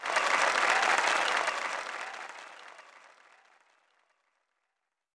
haiClap.ogg